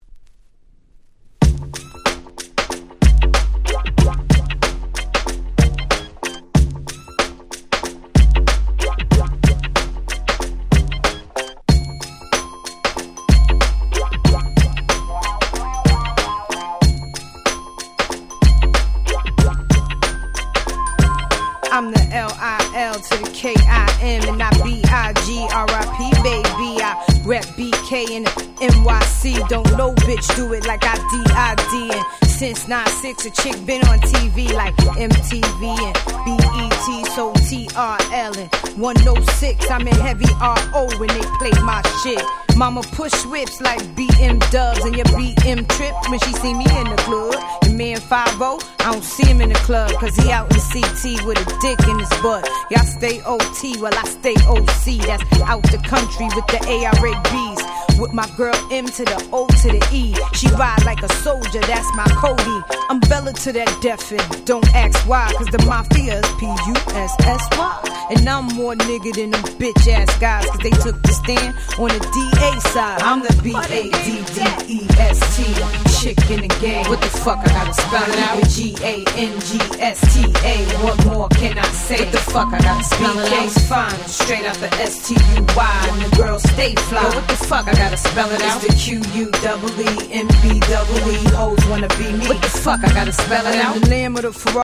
05' Big Hit Hip Hop !!